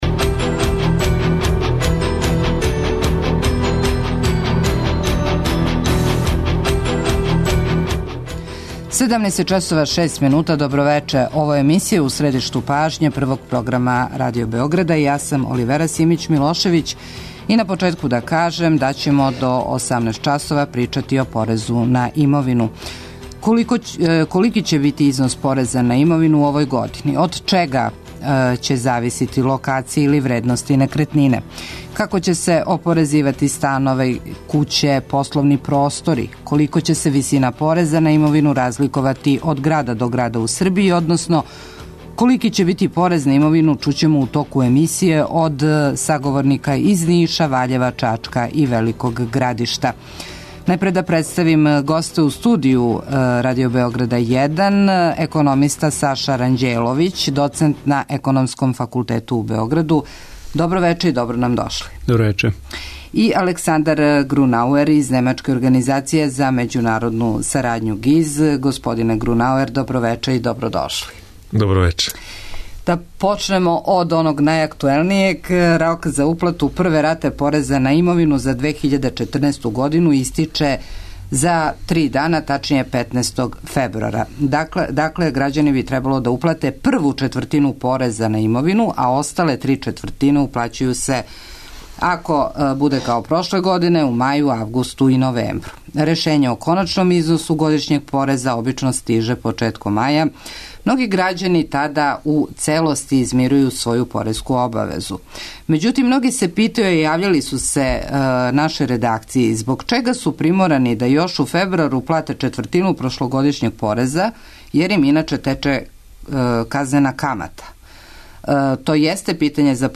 Колика ће бити вредност пореза на имовину чућемо и од саговорника из Ниша, Ваљева, Чачка и Великог Градишта.